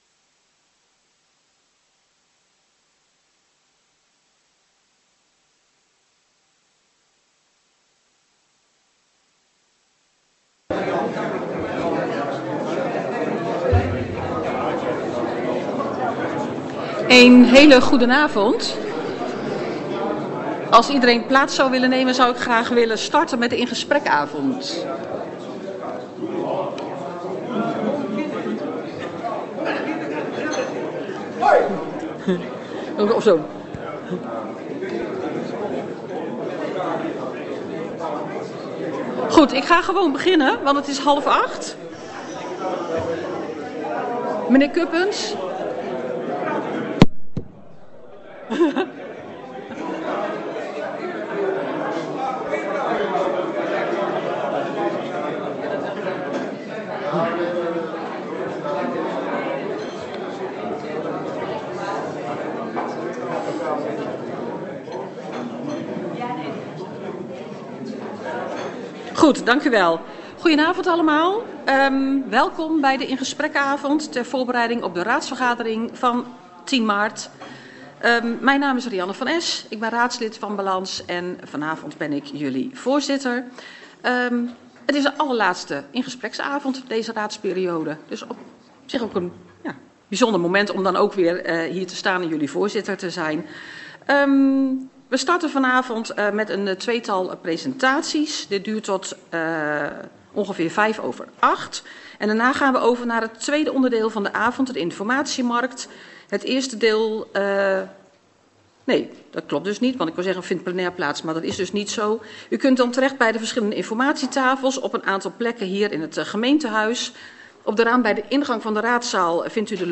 Deze bijeenkomst vindt plaats in het gemeentehuis.
Locatie Raadzaal Boxtel Voorzitter Rianne van Esch Toelichting Wilt u deelnemen aan deze In-gespreksavond?